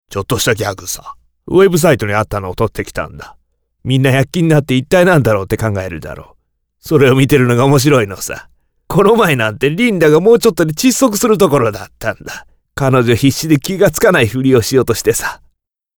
japanischer Sprecher
Sprechprobe: Werbung (Muttersprache):
japanese voice over artist